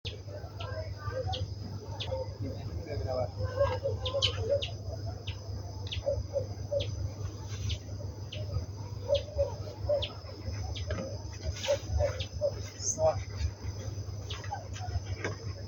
Fueguero Escarlata (Ramphocelus bresilia) - EcoRegistros
Nombre en inglés: Brazilian Tanager
Localidad o área protegida: Florianópolis
Condición: Silvestre
Certeza: Observada, Vocalización Grabada